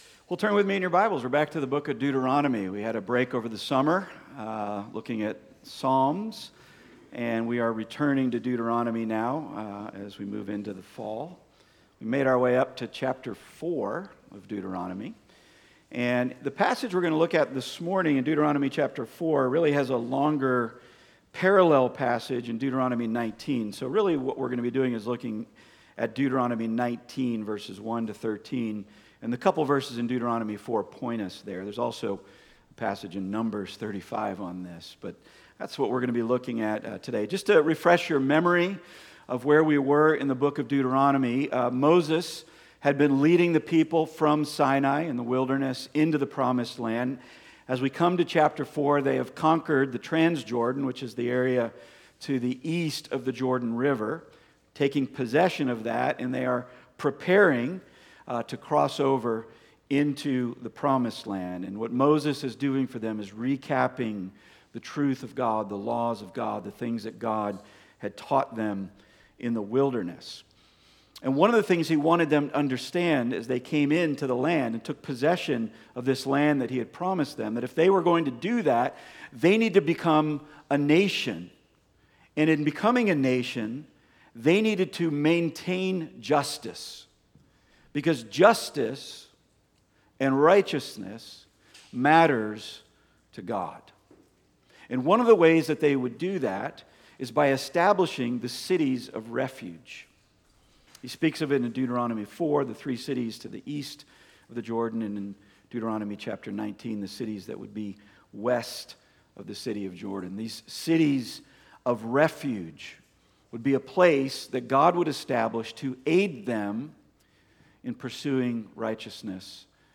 Passage: Deuteronomy 4:41-43, 19:1-13 Service Type: Weekly Sunday